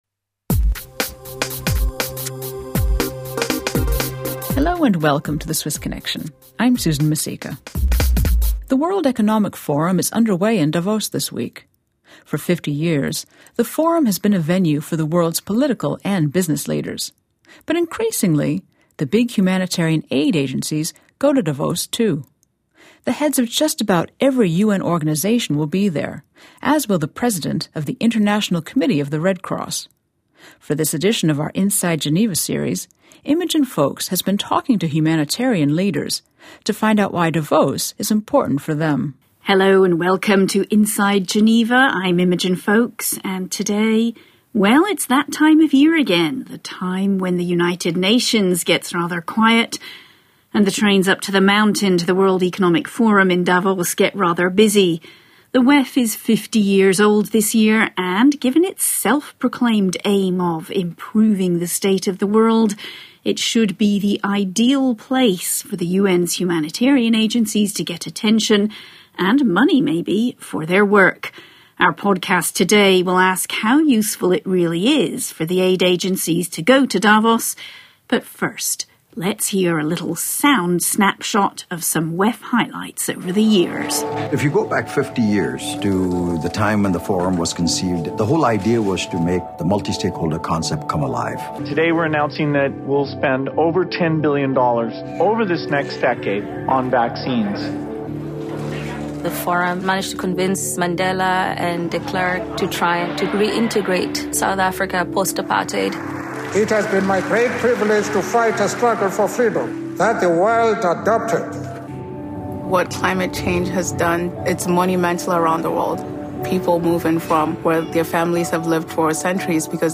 For this edition of The Swiss Connection’s Inside Geneva series, we’ve been talking to humanitarian leaders to find out why Davos is important for them.